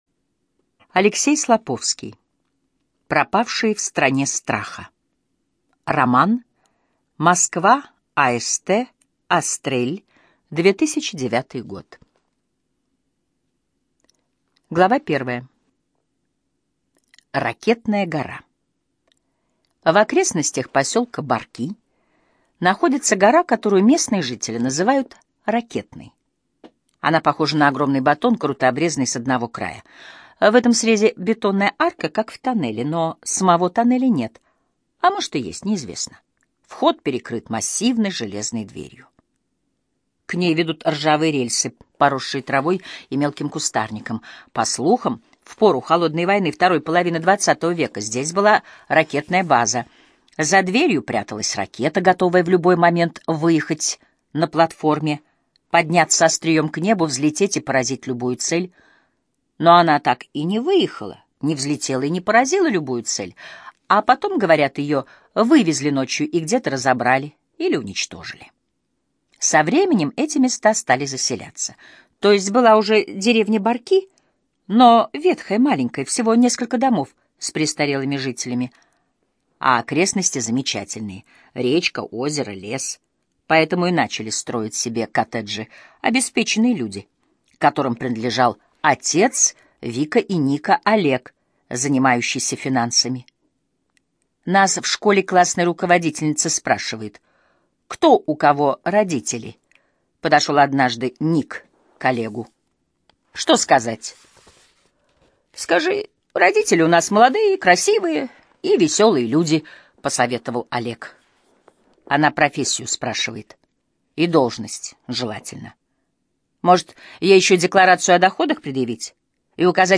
ЖанрФантастика, Детская литература
Студия звукозаписиЛогосвос